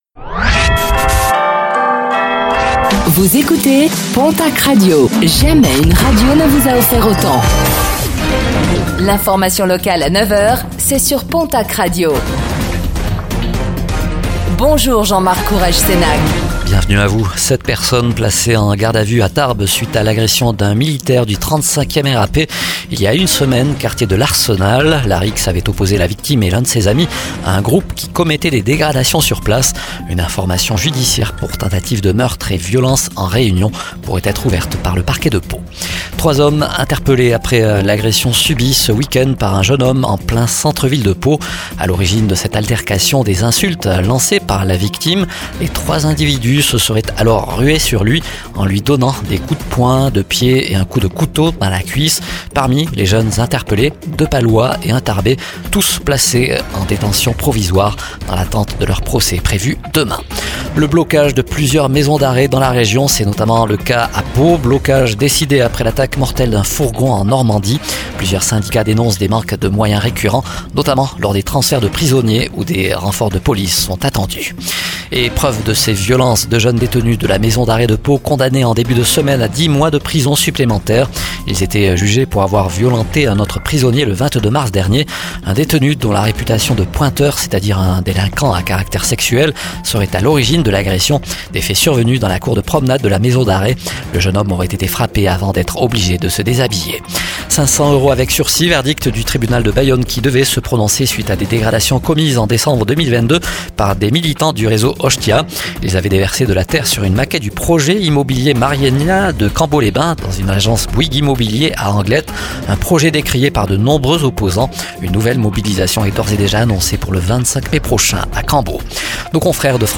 Infos | Mercredi 15 mai 2024